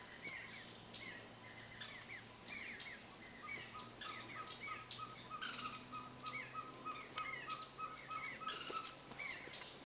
Ferruginous Pygmy Owl (Glaucidium brasilianum)
Life Stage: Adult
Province / Department: Misiones
Location or protected area: Bio Reserva Karadya
Condition: Wild
Certainty: Recorded vocal
cabure-chico.mp3